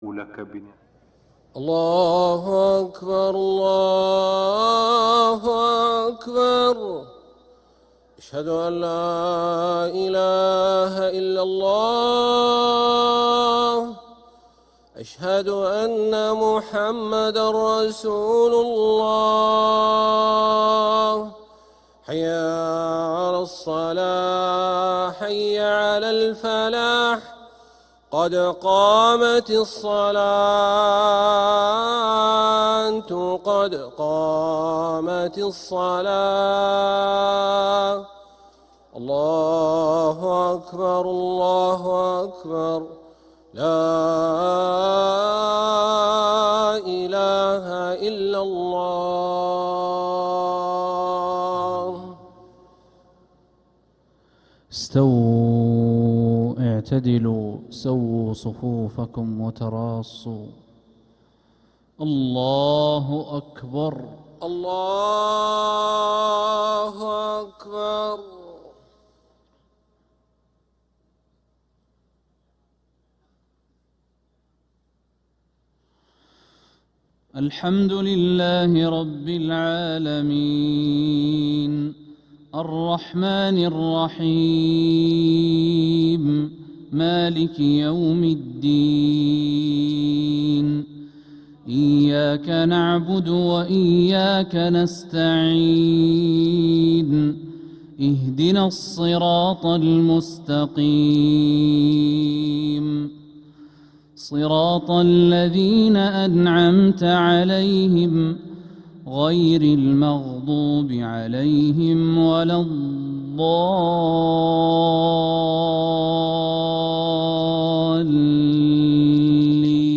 Makkah Maghrib - 07th February 2026